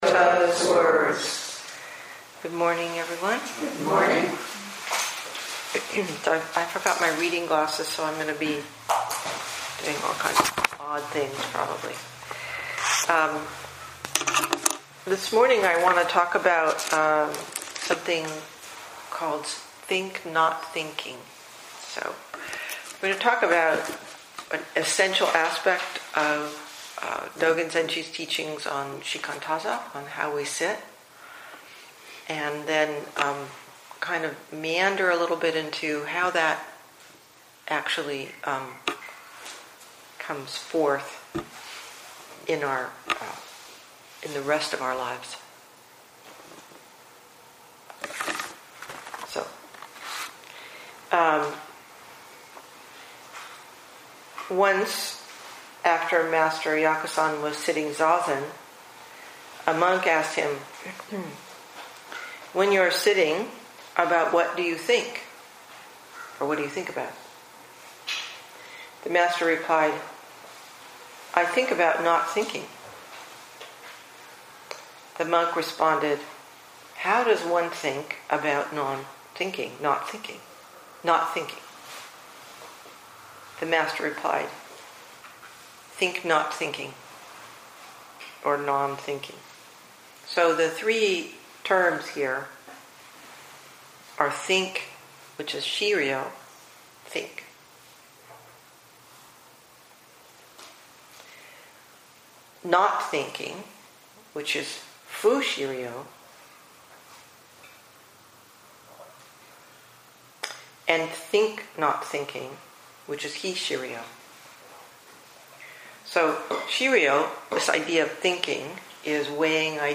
2017 in Dharma Talks